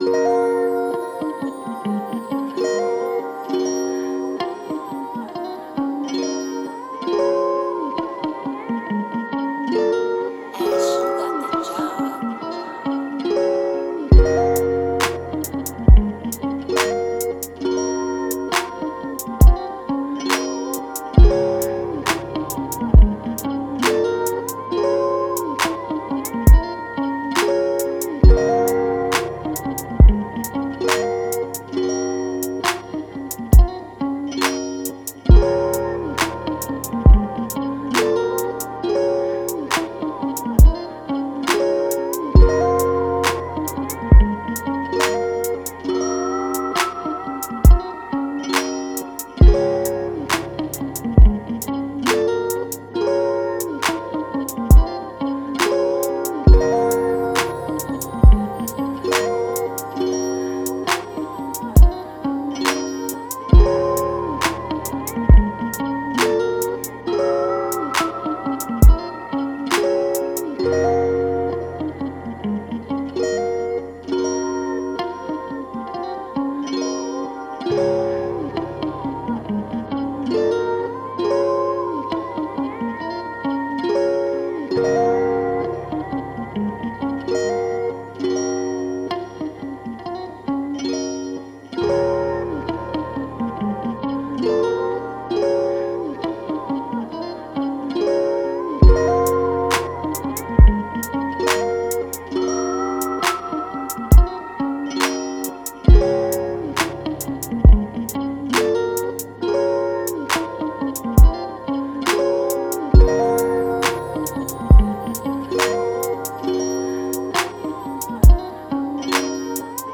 While you check me out, a LoFi track to chill to .